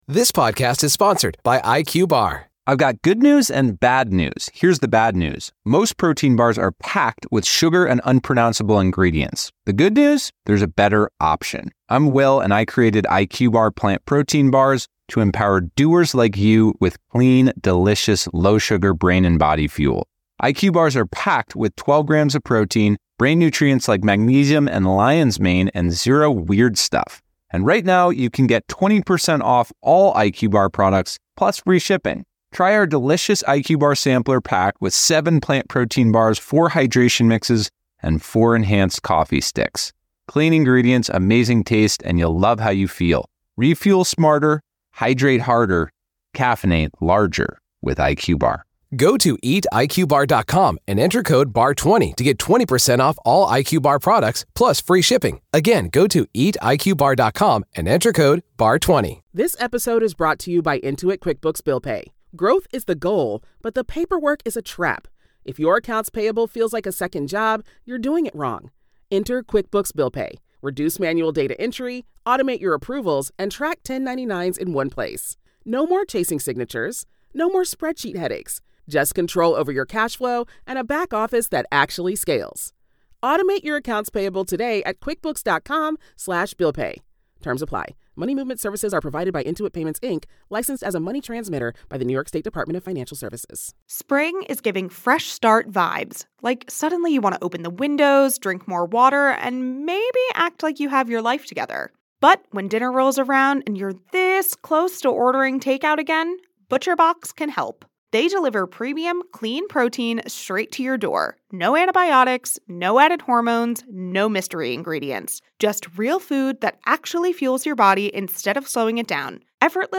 engaged in a discussion